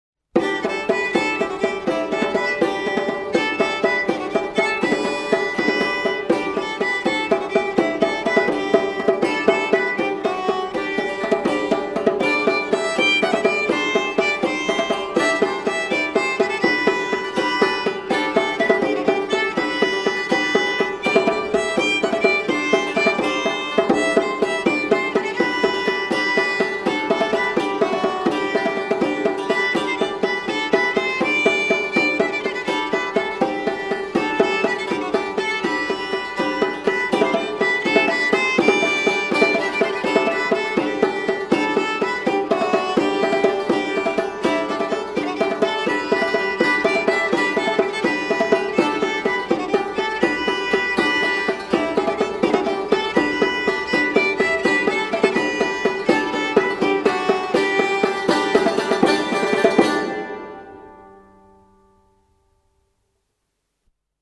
rebec.mp3